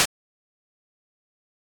noise.ogg